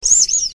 clock02.ogg